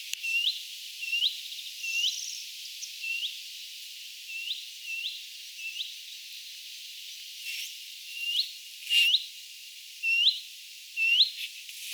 kuvien pajulinnun huomioääntä
kuvien_pajulinnun_huomioaanta.mp3